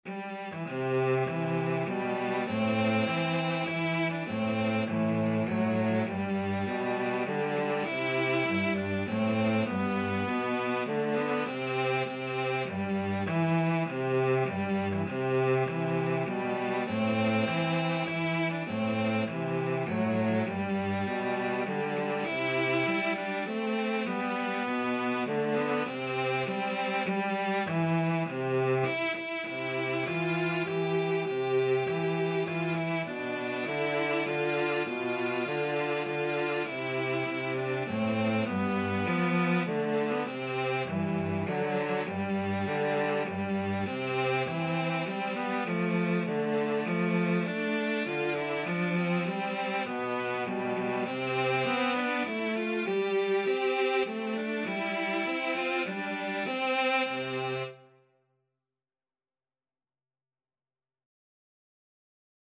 Cello 1Cello 2
3/4 (View more 3/4 Music)